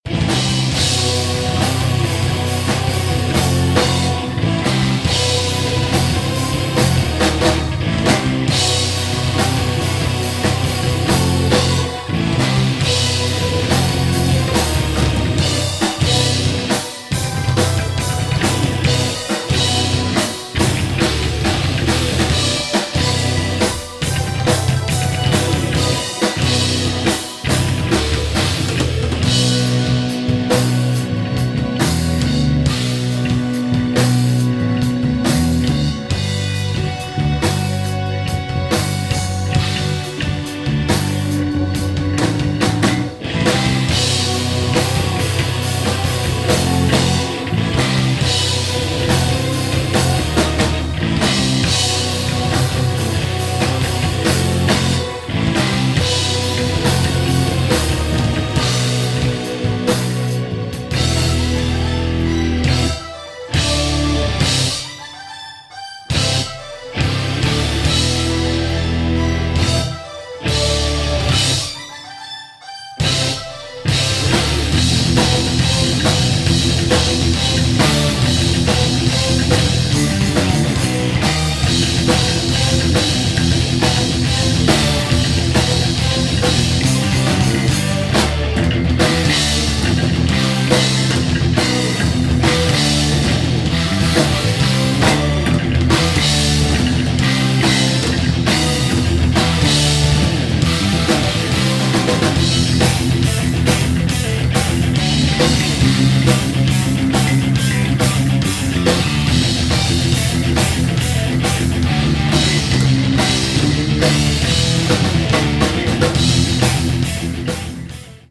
Nahrávku mám zatím jen s kapelou, kousek sem strčím. Je to nahraný na zkoušce na Zoom H1, kterel ležel na židli před kapelou.
Celolampa Hocke v kapele